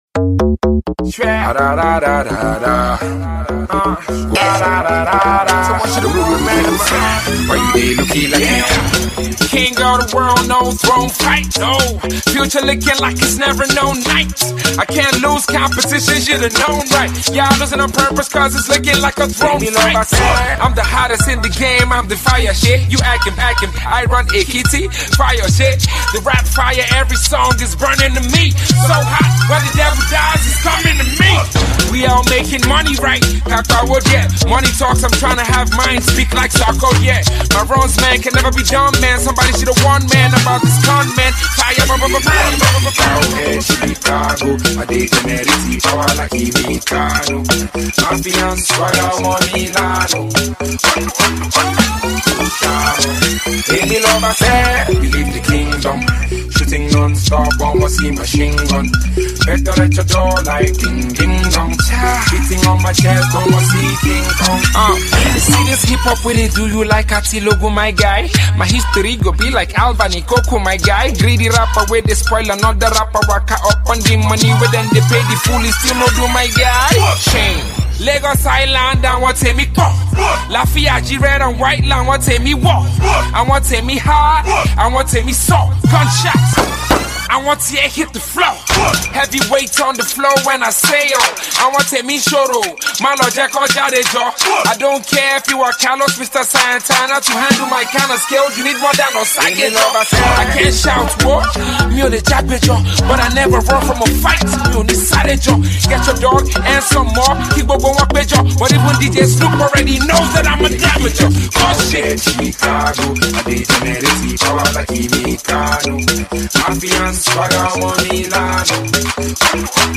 Its a Proper commercial Rap song